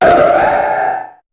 Cri_0359_Méga_HOME.ogg